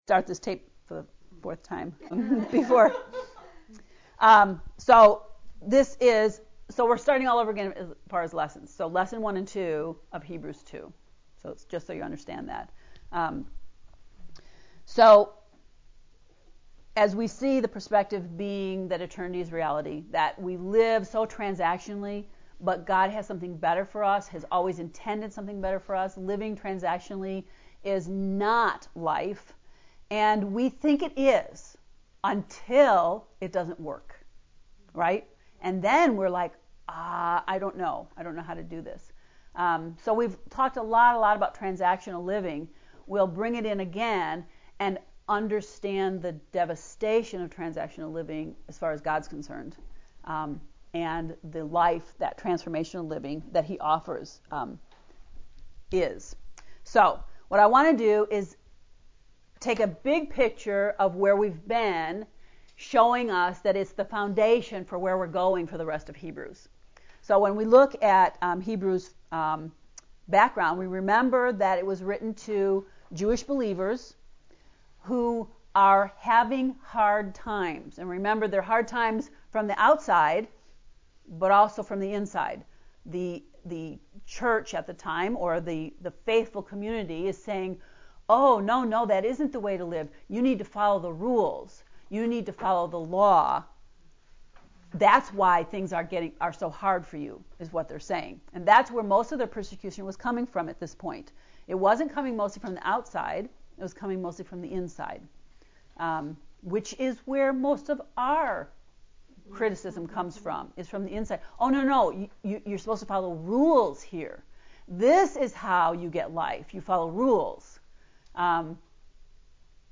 heb-ii-lecture-1-2.mp3